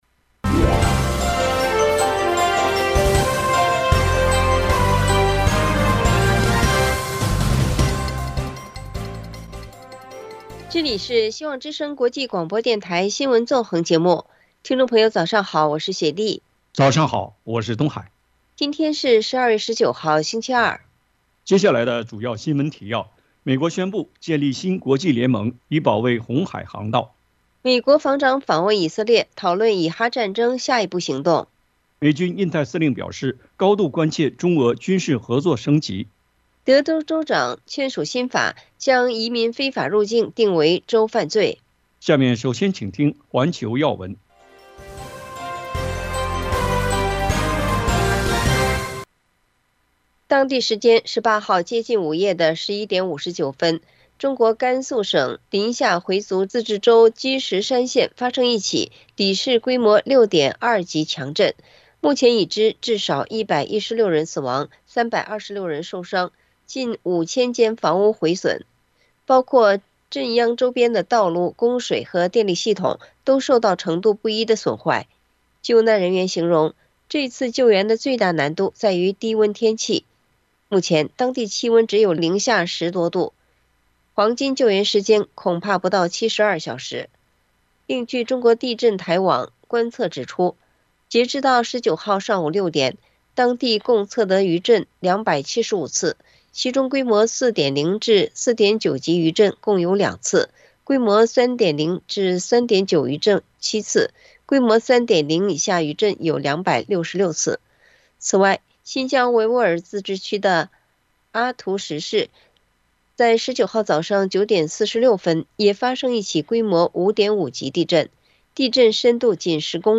希望之聲 - FM96.1 灣區台